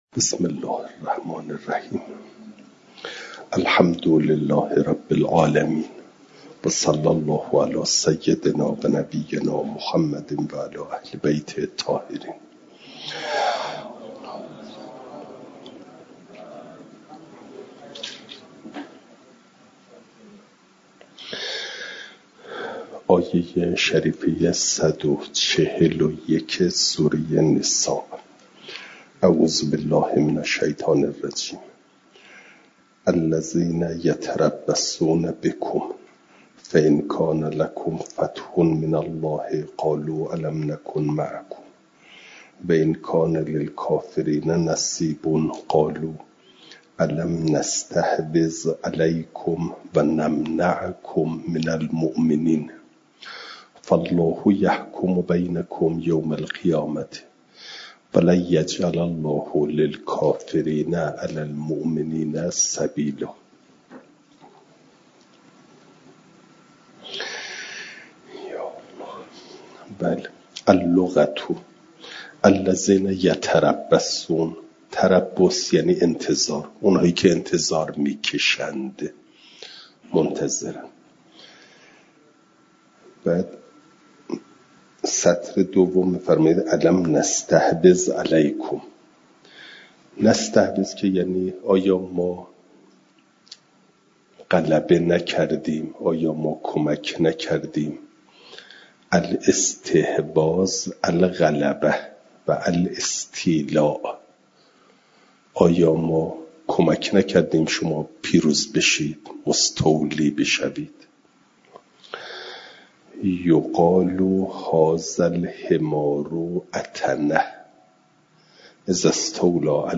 جلسه چهارصدم درس تفسیر مجمع البیان